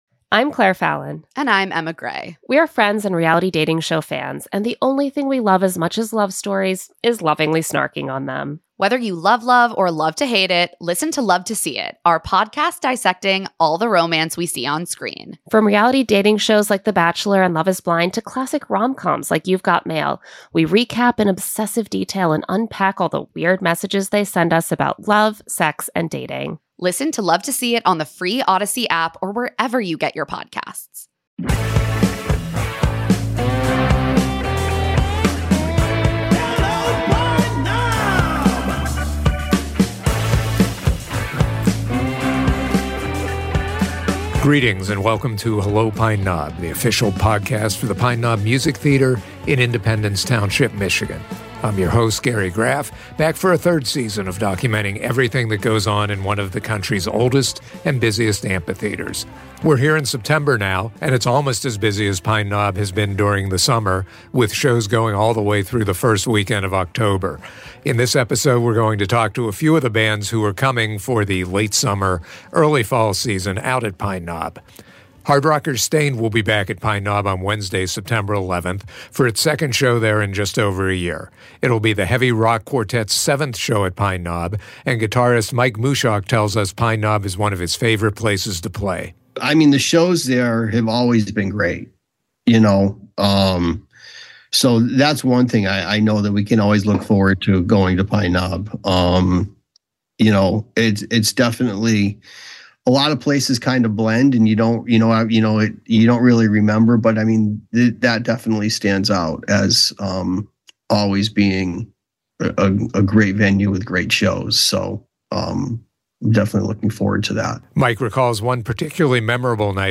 Hard rockers Staind will be back at Pine Knob on Wednesday, September 11, for a second show there in just over a year. It will be the heavy rock quartet's seventh show at Pine Knob, and guitarist Mike Mushok tells us why Pine Knob is one of his favorite places to play.
Frontman Rob Halford is another who has nothing but good things to say about Pine Knob.